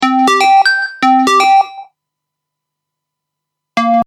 | robot phrase |